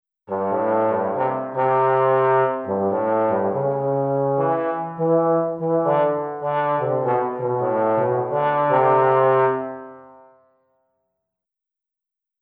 Azulino toca el trombón
trombon_mp3.mp3